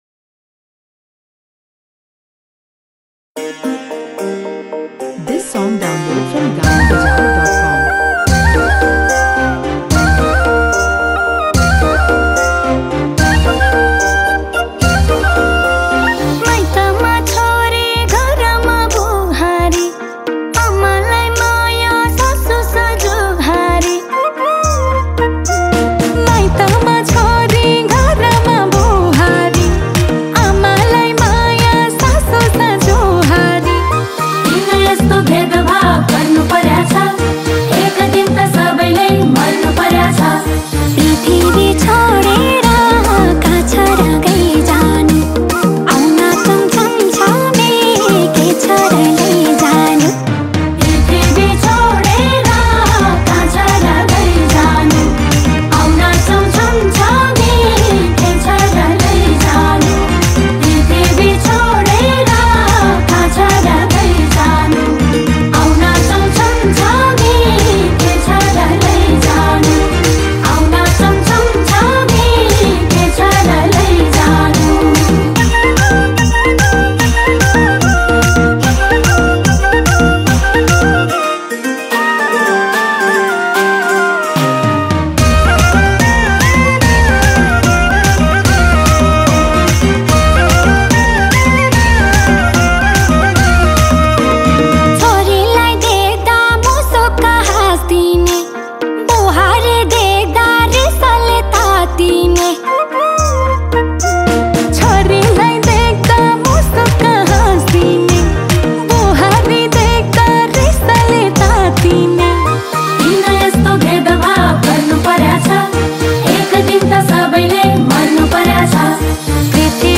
NEPALI TEEJ SONG